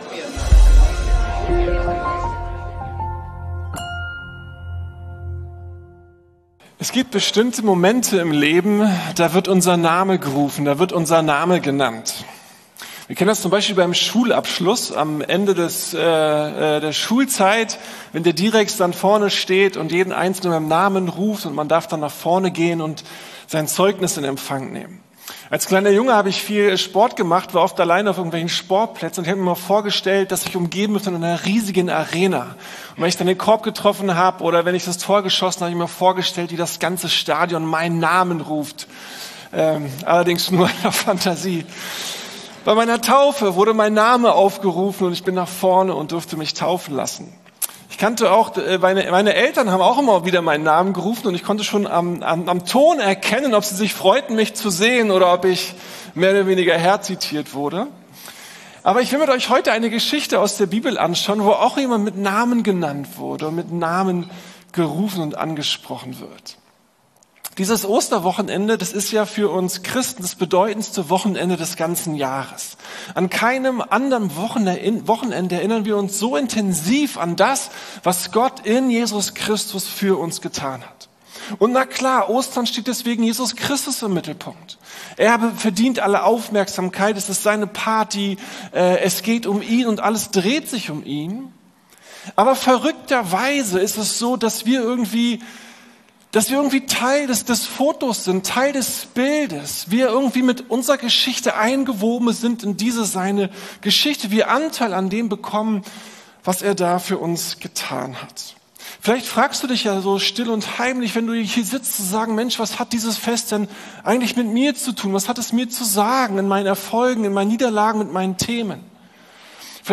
Ostern- Wenn Jesus deinen Namen ruft! ~ Predigten der LUKAS GEMEINDE Podcast